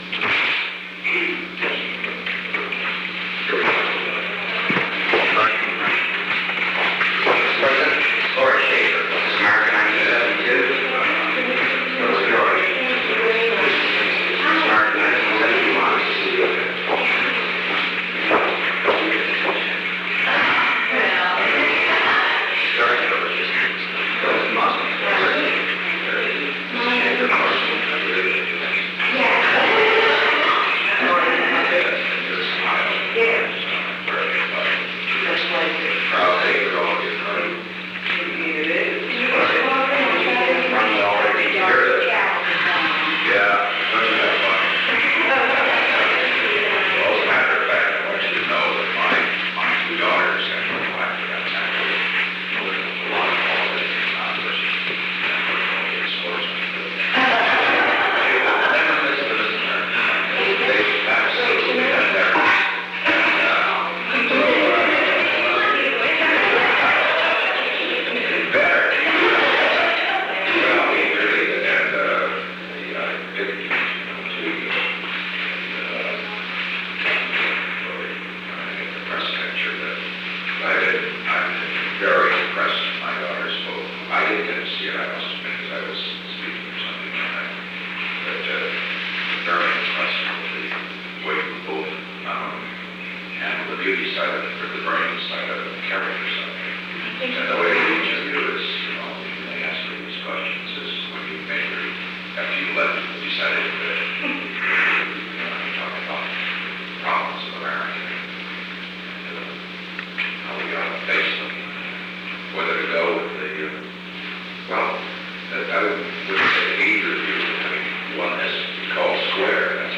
Secret White House Tapes | Richard M. Nixon Presidency